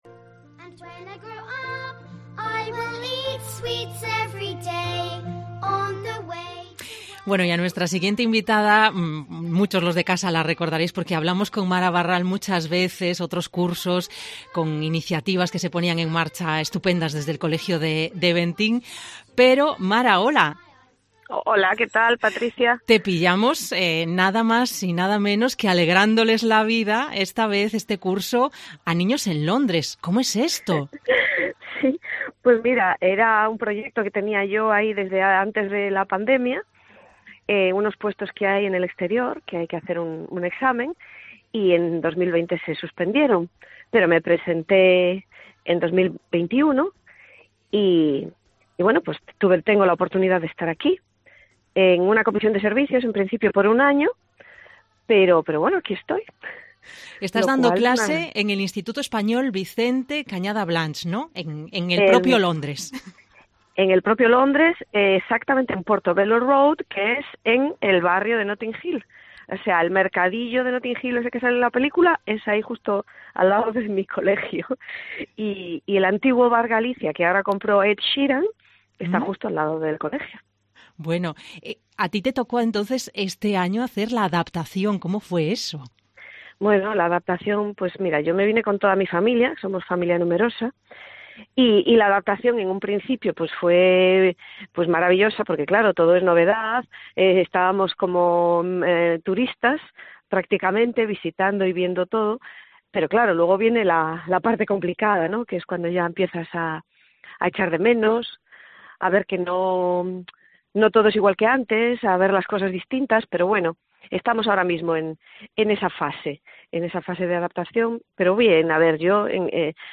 AUDIO: Entrevista con una profesora que ha pasado de dar clase un Ceip en la comarca compostelana a un instituto